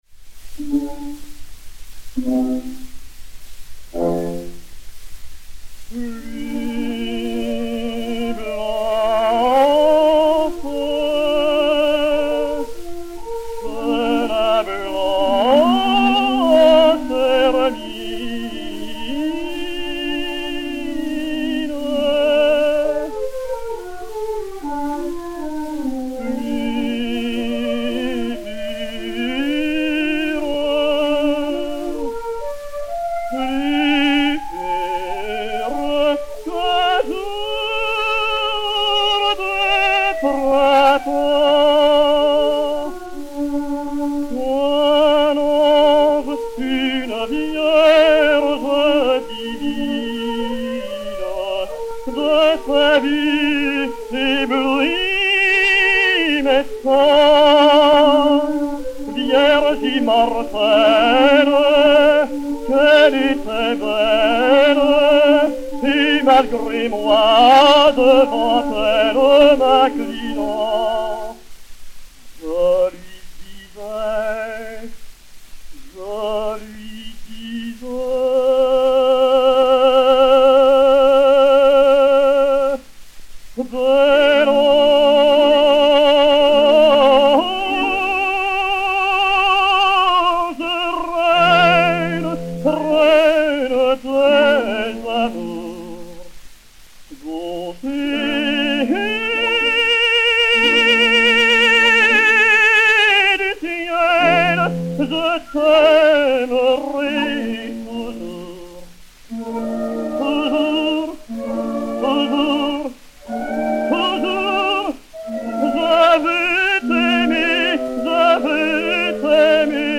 et Orchestre
XP 5060, enr. à Paris en 1910